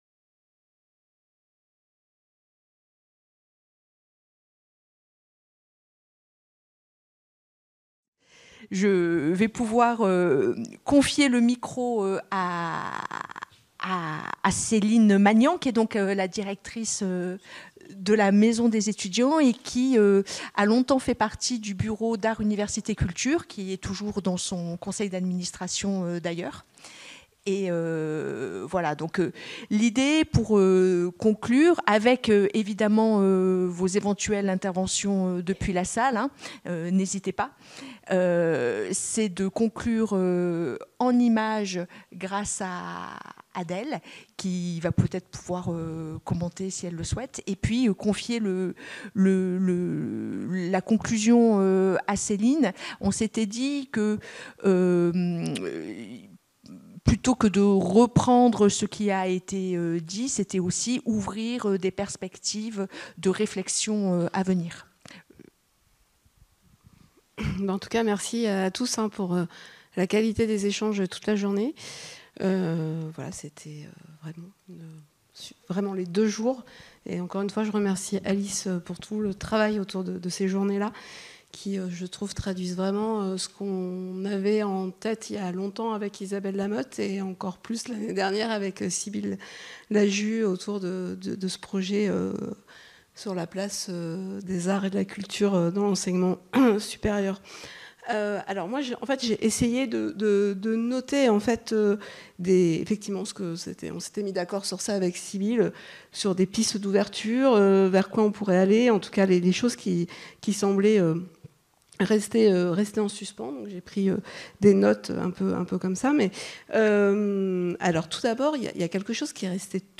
Échanges avec le public